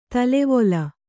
♪ talevola